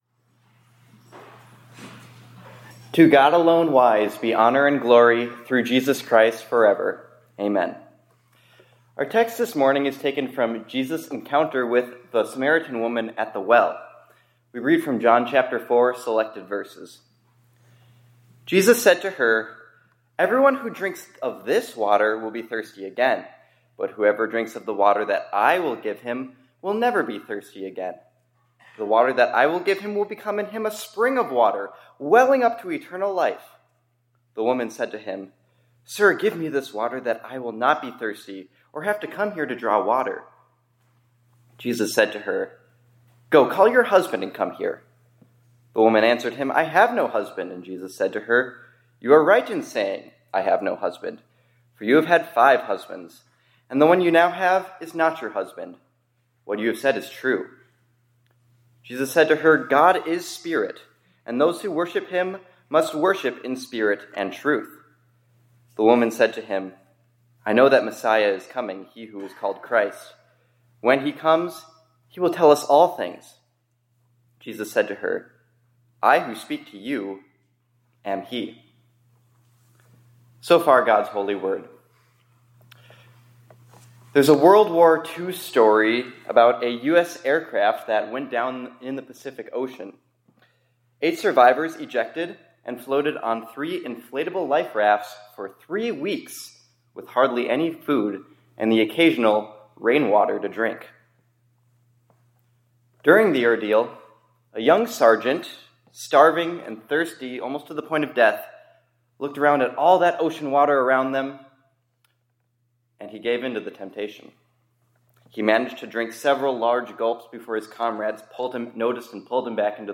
2026-02-02 ILC Chapel — Leave Your Water Jar Behind